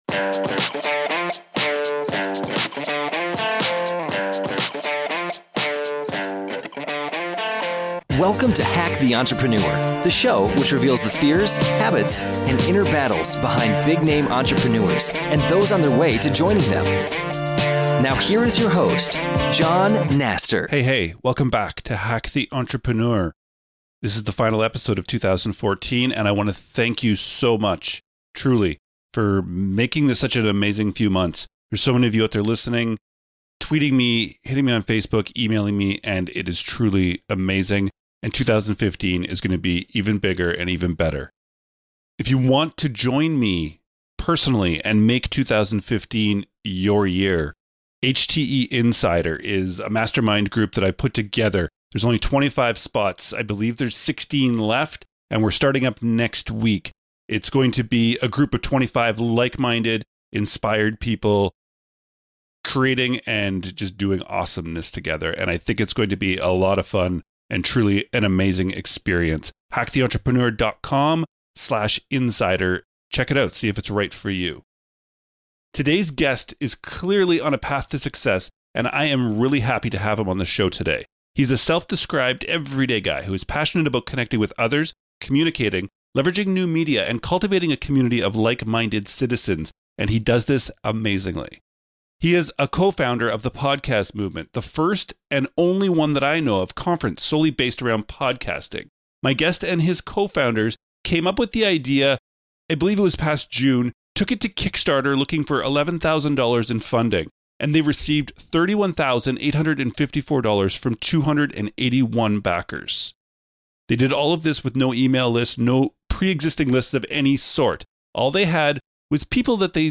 Today’s guest is clearly on a path to success and I am really happy to have him on the show today.